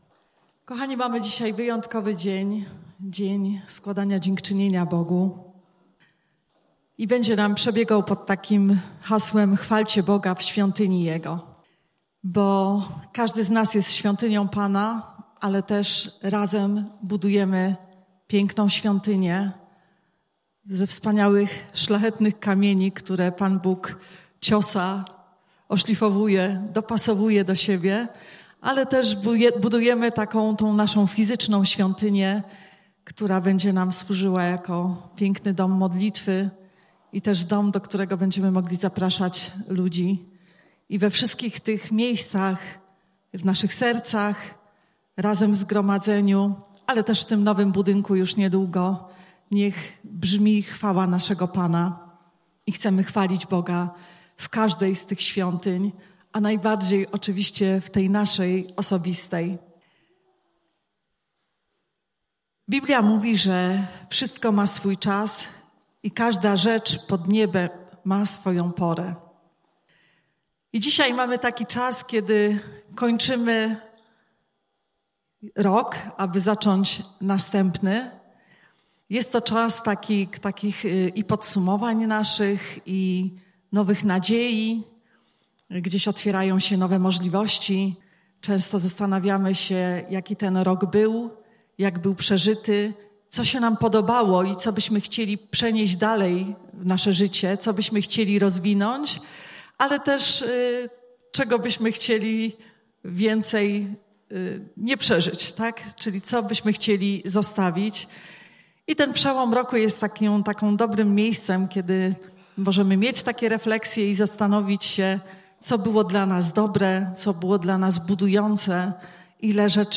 Nabożeństwo dziękczynne – 29.12.2024, 10:30 KZ Betlejem